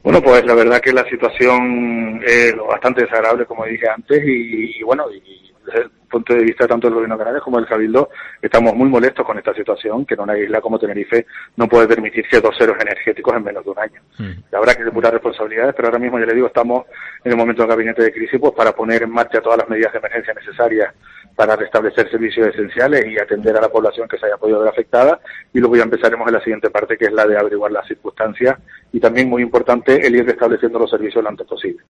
Enrique Arriaga, vicepresidente del Cabildo de Tenerife